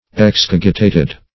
Excogitate \Ex*cog"i*tate\v. t. [imp.